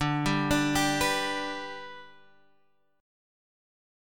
Listen to D64 strummed